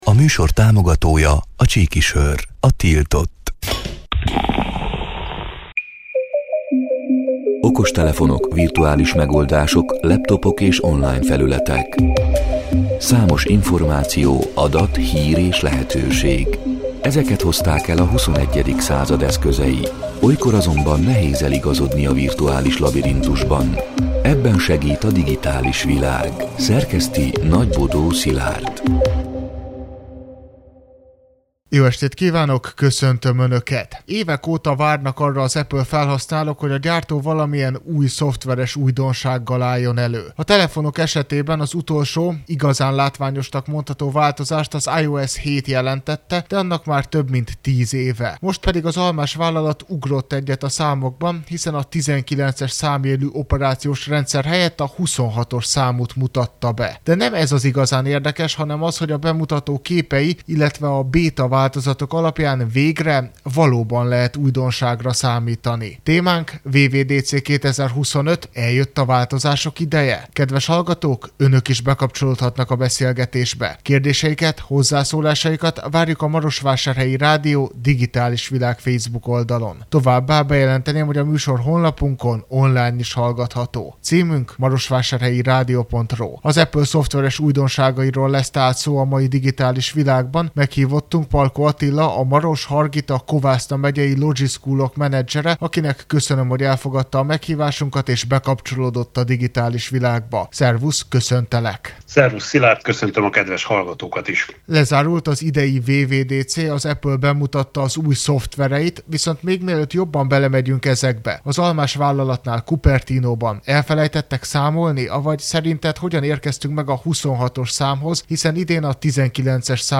A Marosvásárhelyi Rádió Digitális Világ (elhangzott: 2025. június 24-én, kedden este nyolc órától) c. műsorának hanganyaga: